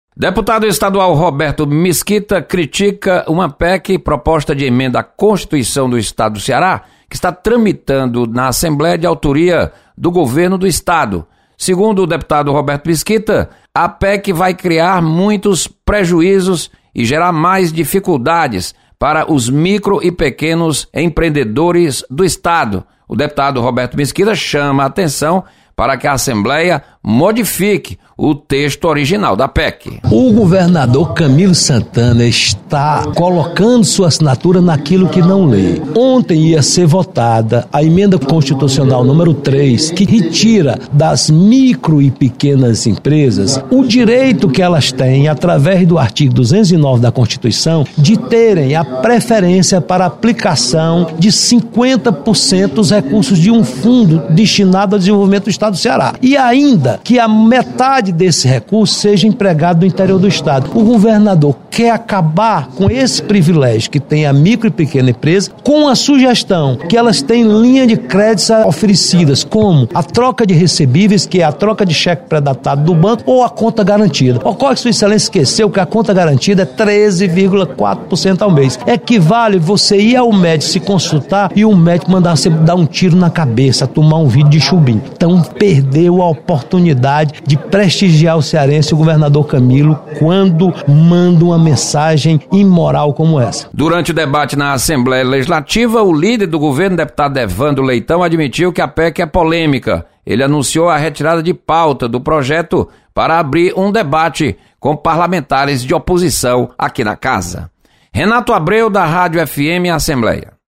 Deputado Roberto Mesquita questiona PEC que modifica Fundo de Desenvolvimento do Estado. Repórter